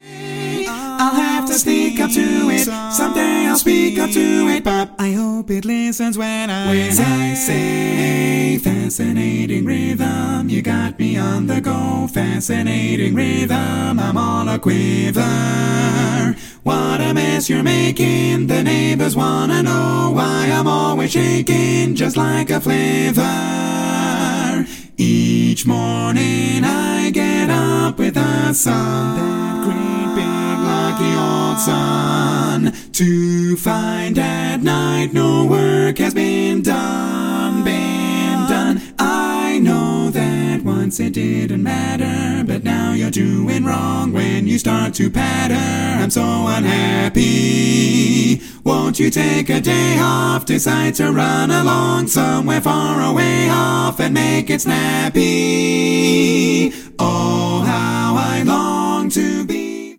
Category: Male